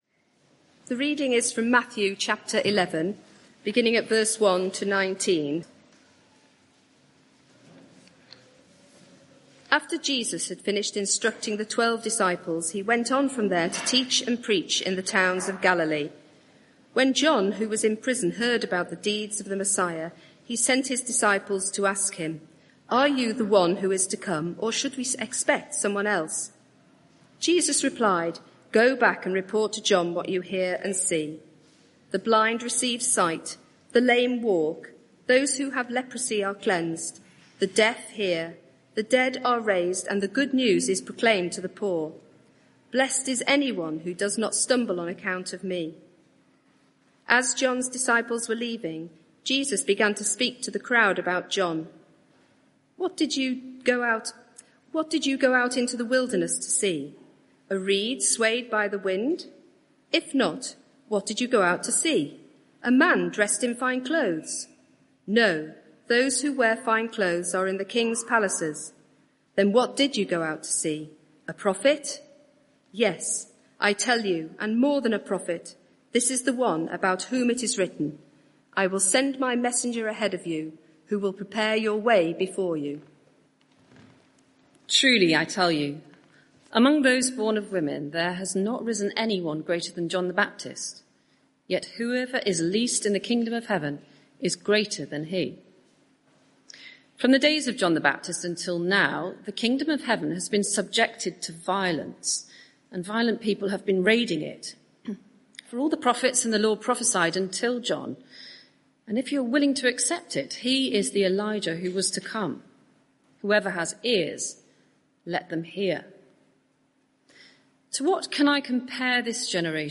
Media for 6:30pm Service on Sun 29th Jun 2025 18:30 Speaker
Sermon (audio) Search the media library There are recordings here going back several years.